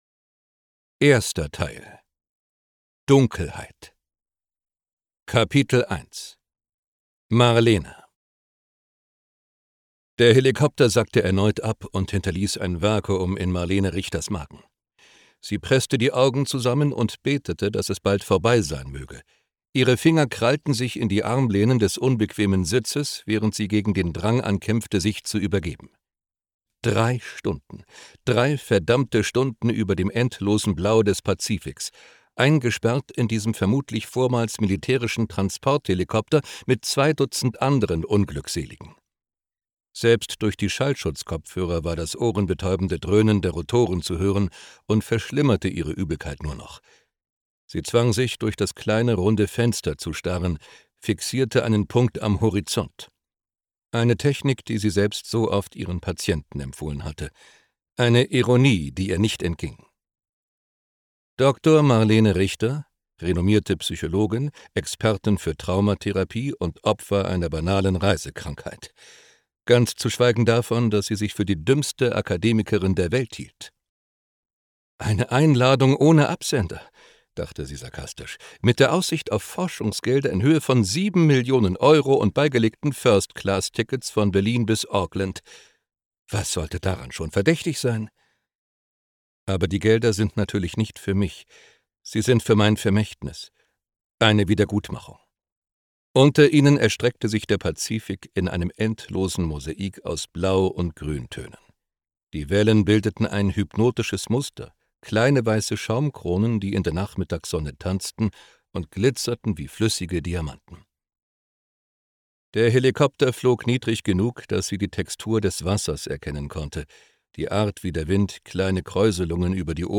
Das neue spannende Sci-Fi-Hörbuch von Joshua Tree
Gekürzt Autorisierte, d.h. von Autor:innen und / oder Verlagen freigegebene, bearbeitete Fassung.